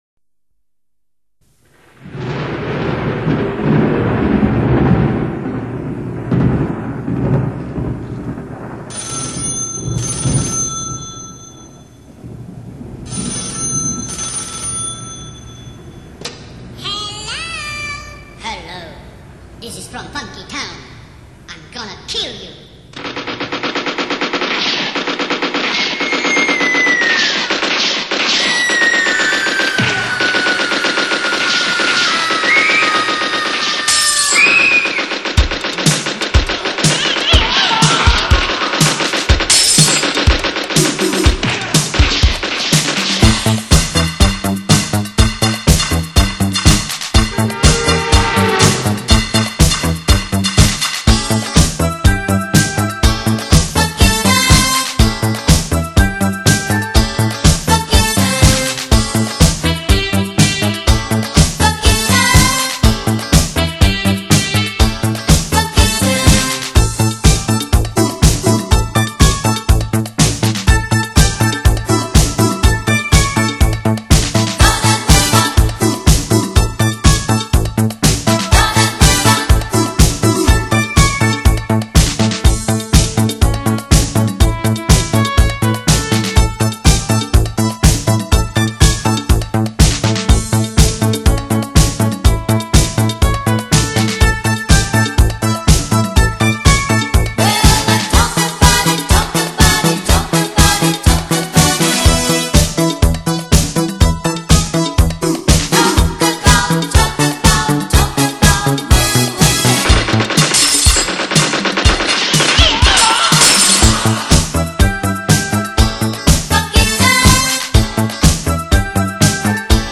西洋金曲 串烧45转